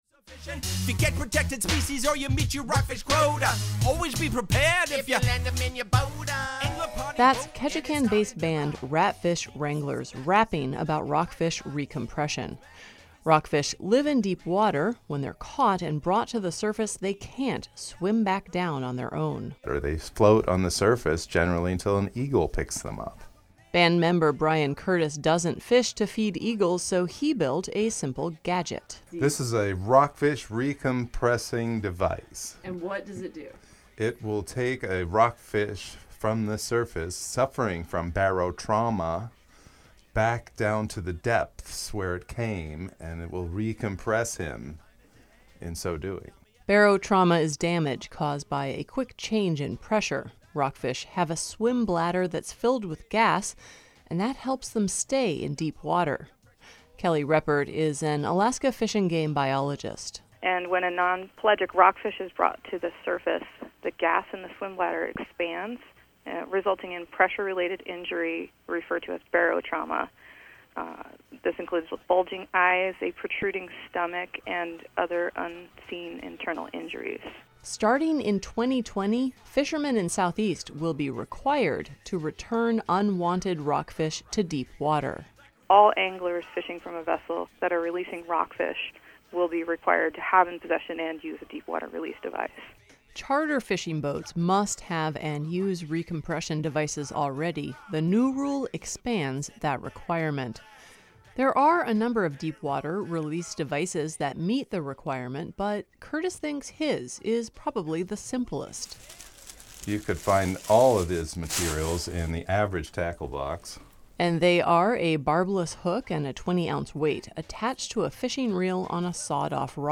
As KRBD’s contribution to CoastAlaska’s “Alaska Made” series, we have this report about an easy-to-build gadget made just for recompression.